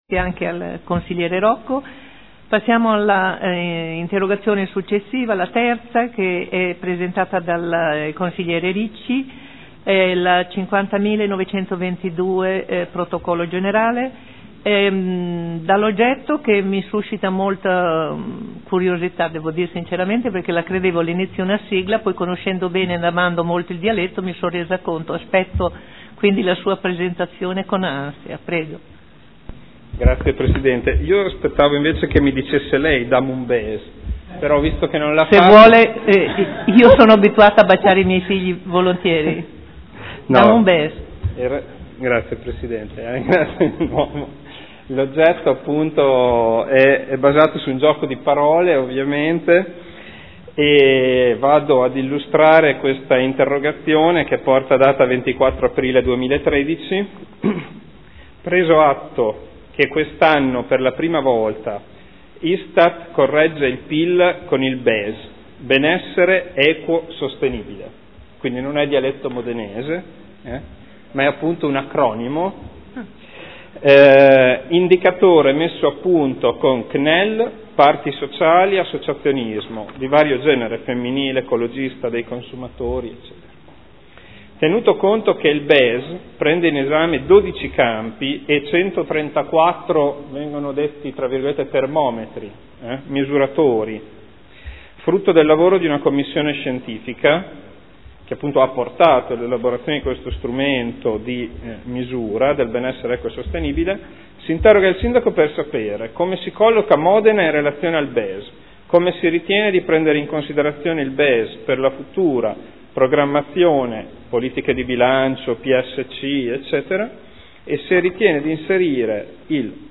Seduta del 10/06/2013 Interrogazione del consigliere Ricci (SEL) avente per oggetto: “Dam un BES”